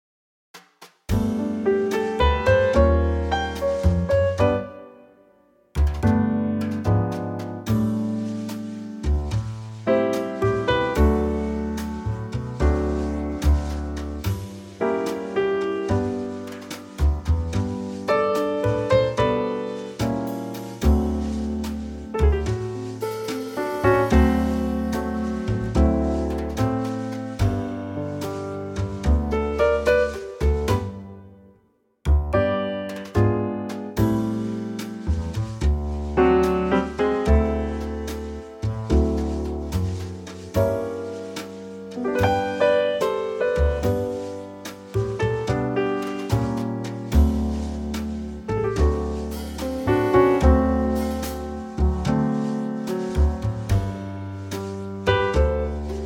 Unique Backing Tracks
key - G - vocal range - G to A
Here's a lovely Trio arrangement.
Same arrangement as F2608 except a full tone lower.